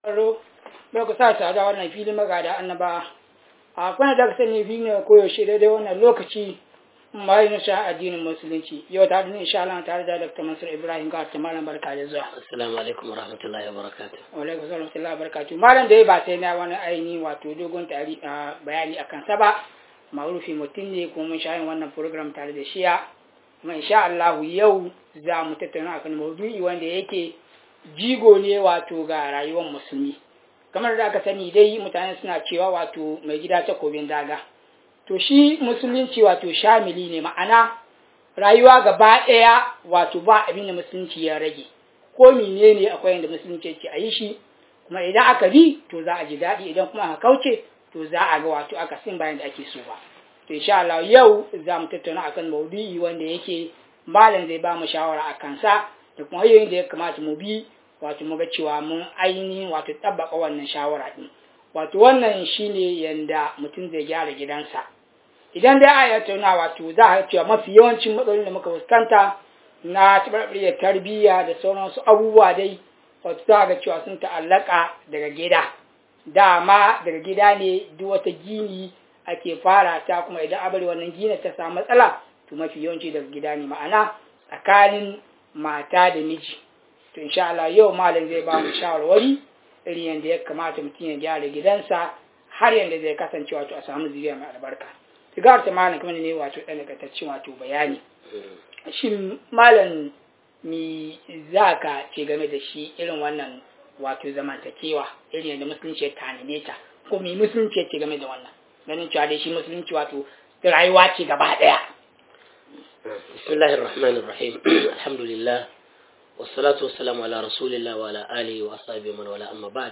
MAGADA ANNABAWA - MUHADARA